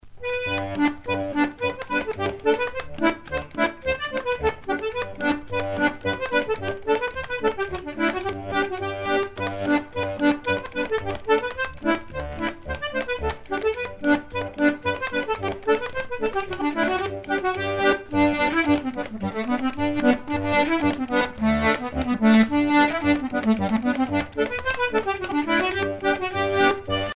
danse : two step
Pièce musicale éditée